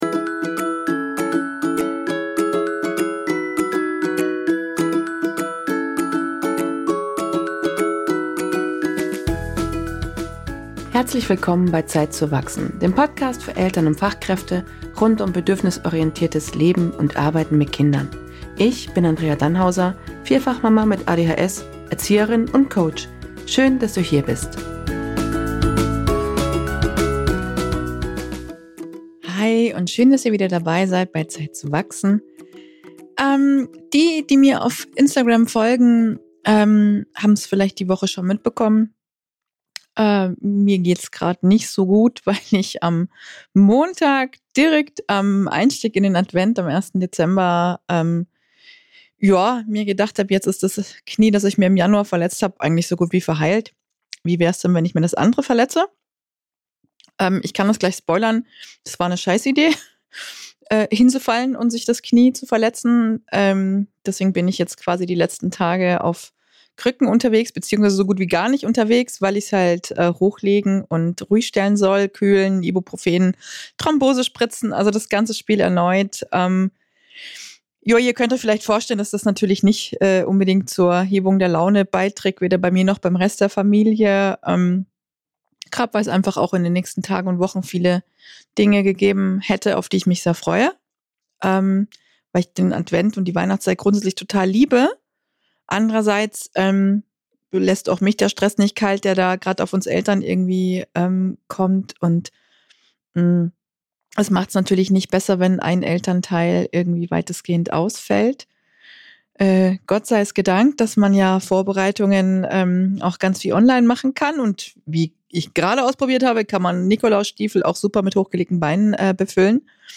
Solofolge